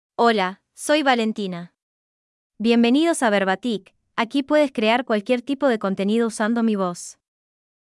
Valentina — Female Spanish (Uruguay) AI Voice | TTS, Voice Cloning & Video | Verbatik AI
Valentina is a female AI voice for Spanish (Uruguay).
Voice sample
Listen to Valentina's female Spanish voice.
Female
Valentina delivers clear pronunciation with authentic Uruguay Spanish intonation, making your content sound professionally produced.